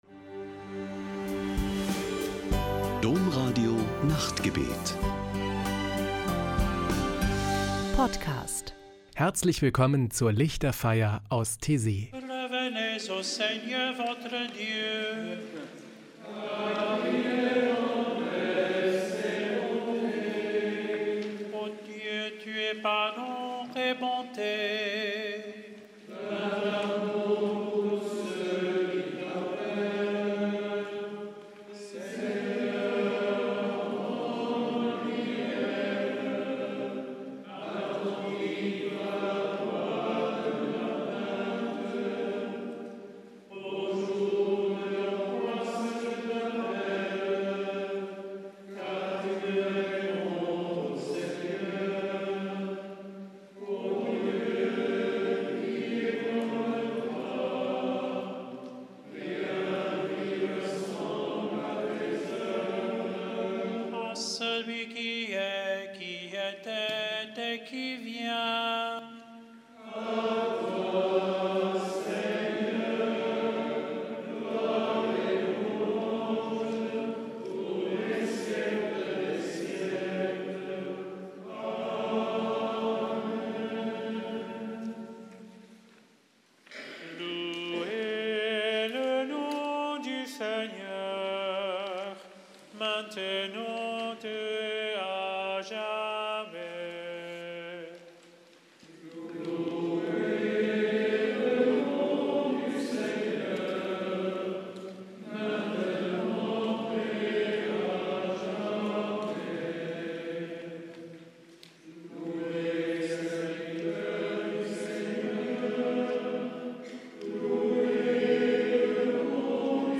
Die Lichterfeier aus Taizé: Spirituelle Gesänge und Gebete
Ein Höhepunkt jede Woche ist am Samstagabend die Lichterfeier mit meditativen Gesängen und Gebeten.